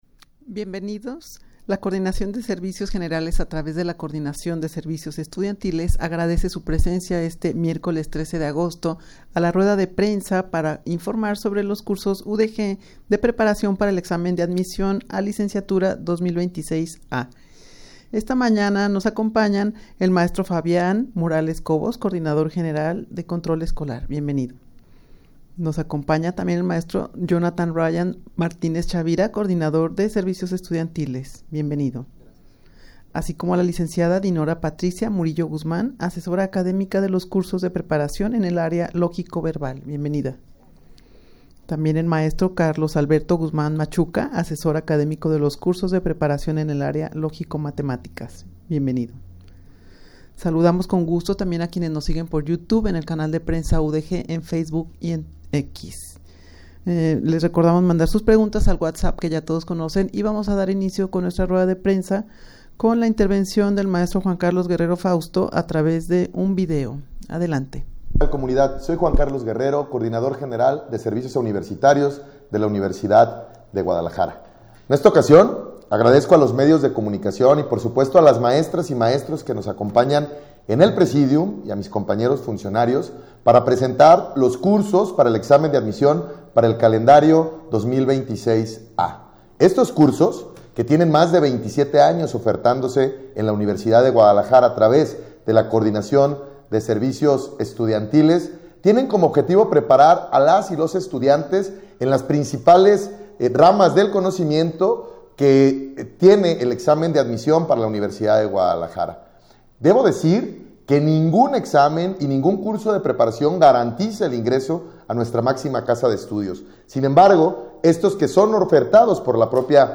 Audio de la Rueda de Prensa
rueda-de-prensa-para-informar-sobre-los-cursos-udeg-de-preparacion-para-el-examen-de-admision-a-licenciatura-2026-a.mp3